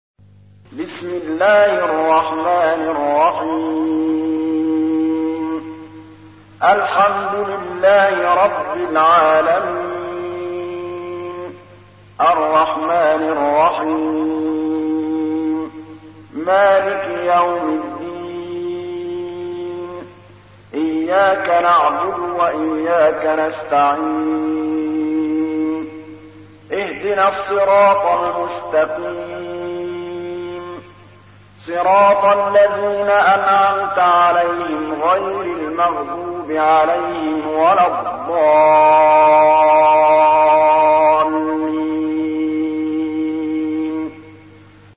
تحميل : 1. سورة الفاتحة / القارئ محمود الطبلاوي / القرآن الكريم / موقع يا حسين